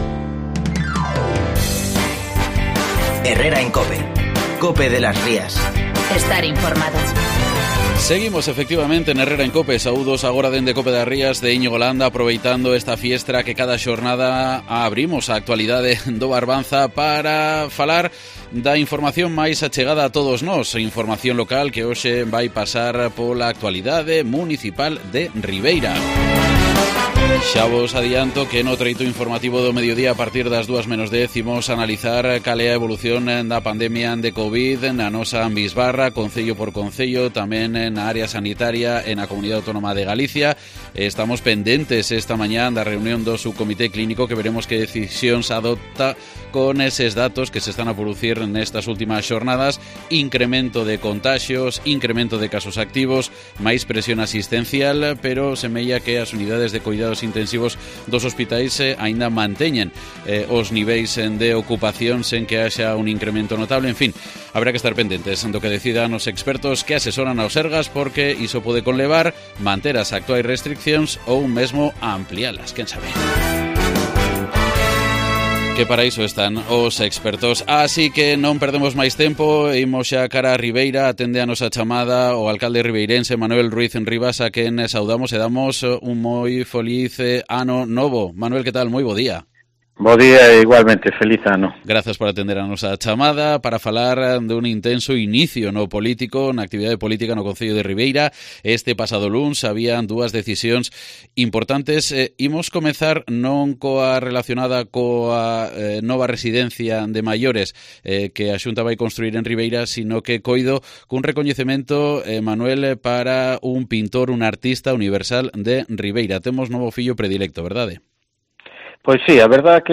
Escucha la entrevista íntegra en COPE de las Rías con el alcalde de Ribeira, Manuel Ruiz Rivas